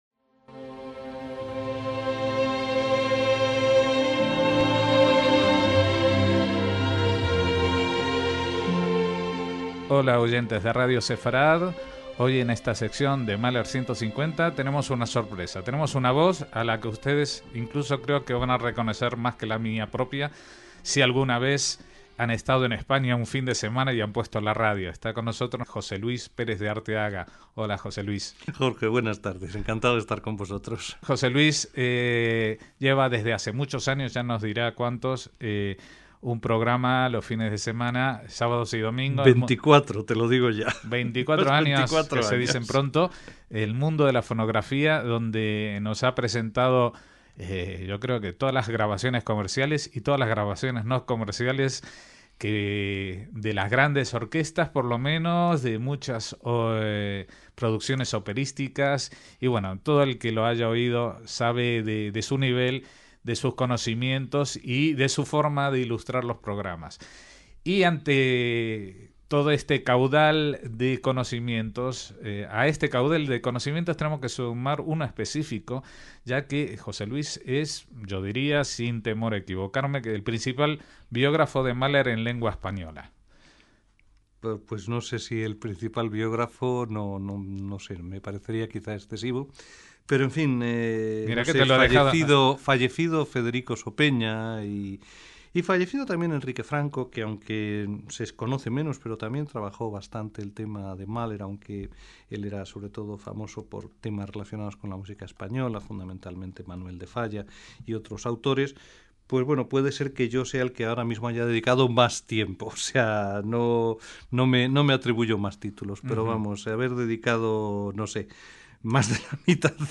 Pero, por encima de todo, un gran persona y amigo al que echaremos de menos y al que dedicamos esta reposición de su paso por nuestros micrófonos